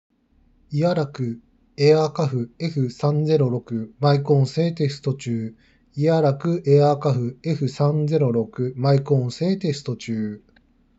マイク性能はそこそこ
✅「Earaku AirCuff F306」のマイクテスト